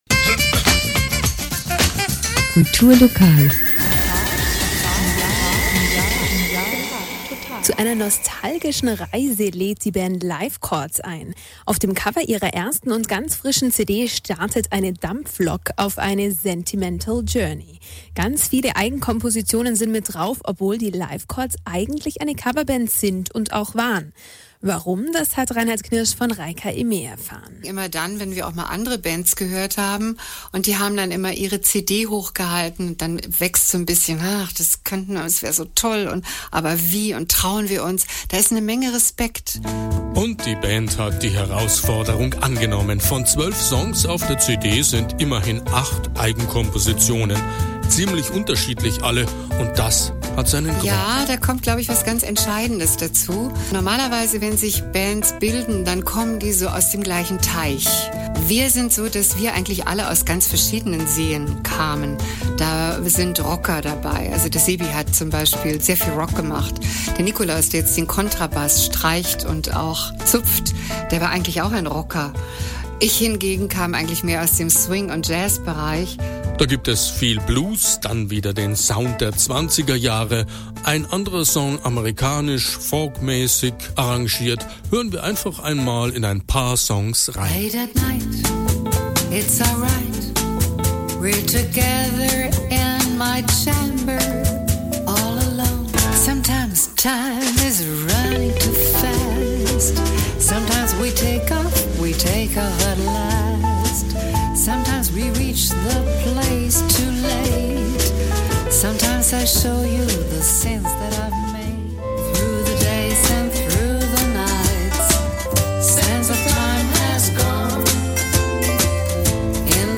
2. Interview Radio Regenbogen